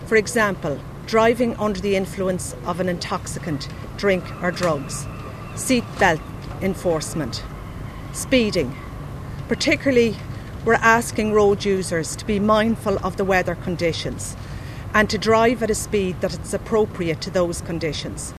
Assistant Commissioner for Roads Policing Catharina Gunne, says they’ll be looking for all forms of illegal activity among motorists……….